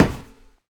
RunMetal1.ogg